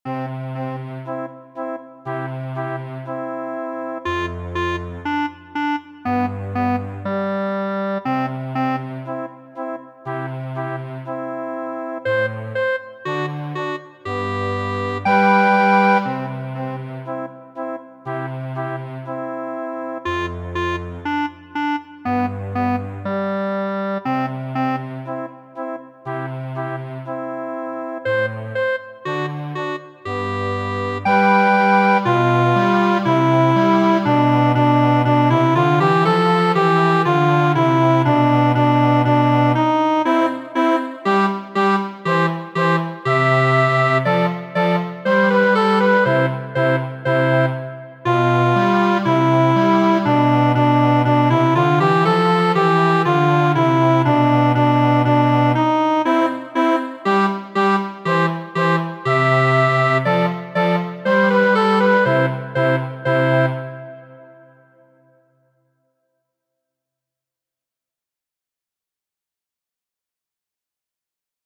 Muziko